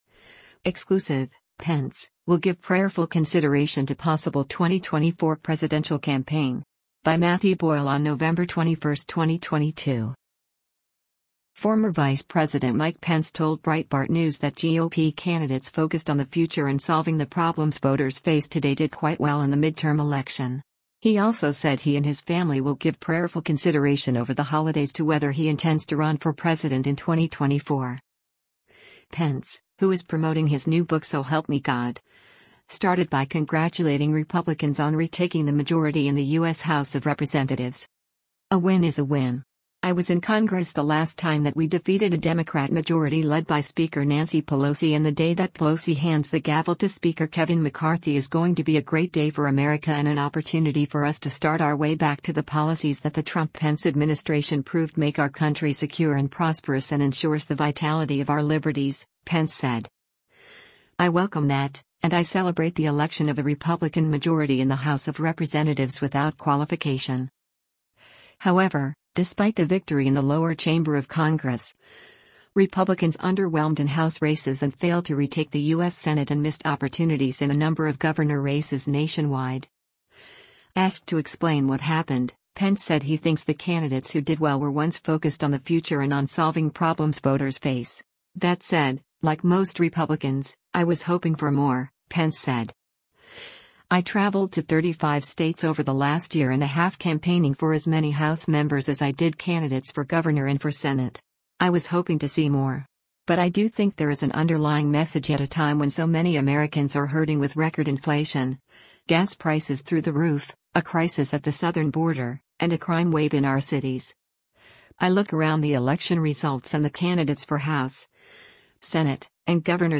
Former Vice President Mike Pence told Breitbart News that GOP candidates focused on the future and solving the problems voters face today “did quite well” in the midterm election.